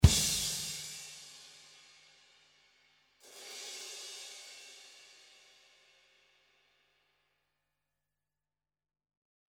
Dirty rock style drum loop in 76 bpm.
There are a lot of loops with ride cymbals and there are some loops with x-stick.
There are 14 loop variations of tom fills.